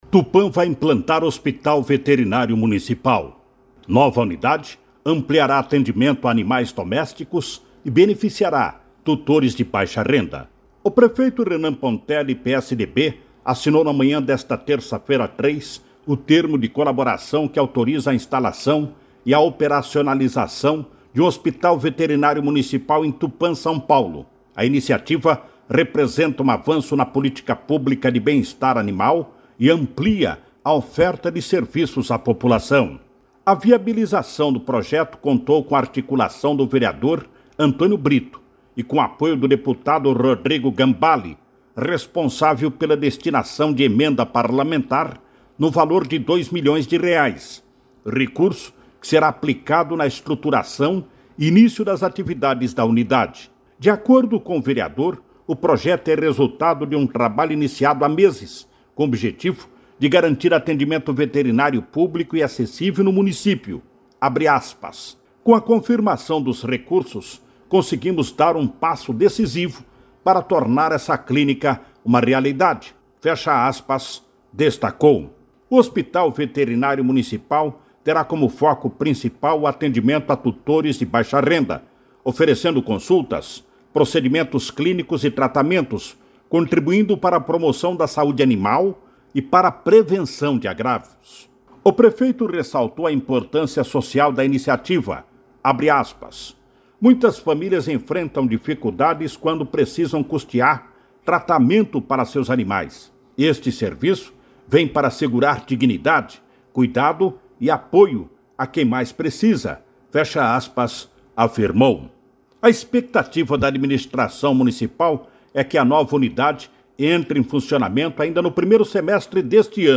Nova unidade ampliará atendimento a animais domésticos e beneficiará tutores de baixa renda – ouça matéria